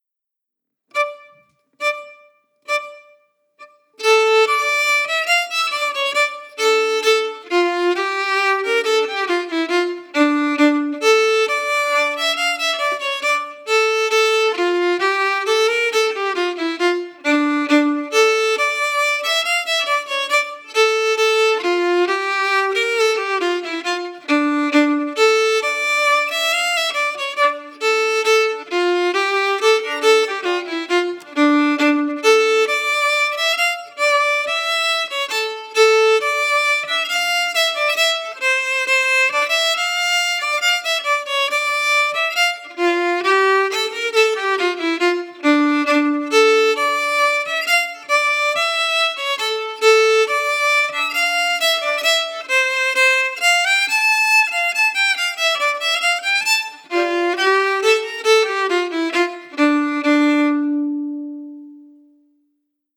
Key: D-minor
Form: Scottish Reel Song
played slowly for learning
Genre/Style: Reel, Song